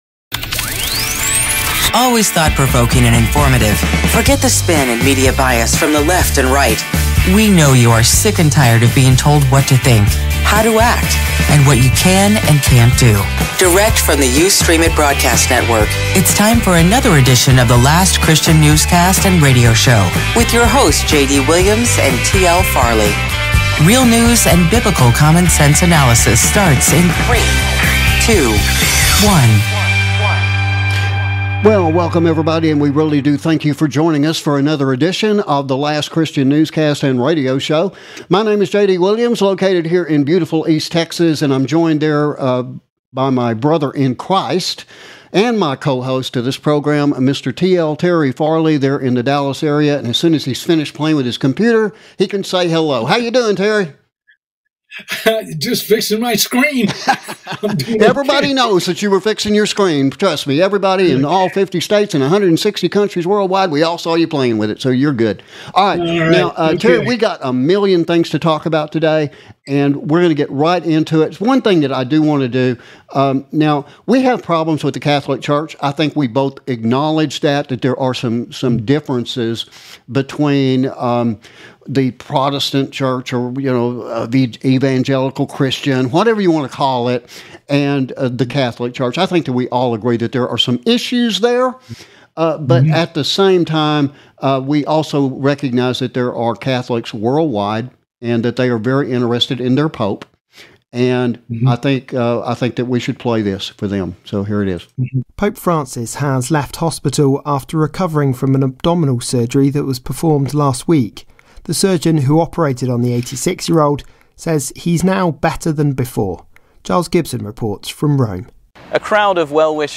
LC Newscast June 17th 2023